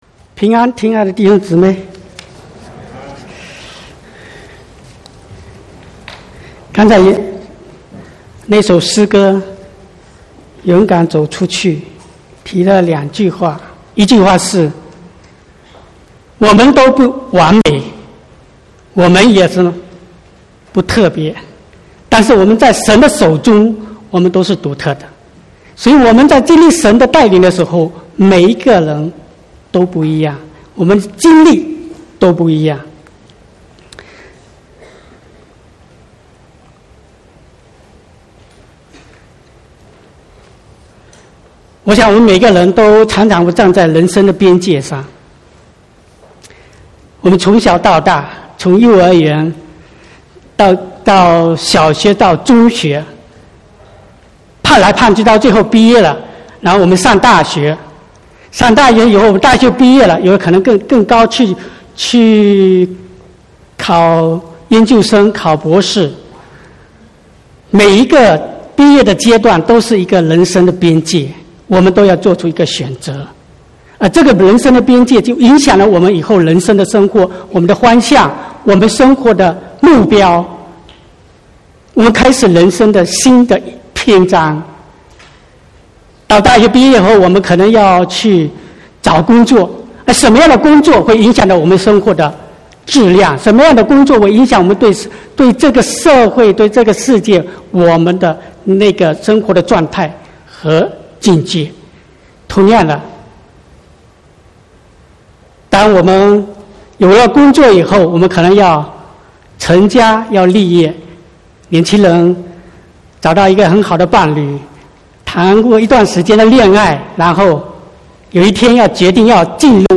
28/4/2019 國語堂講道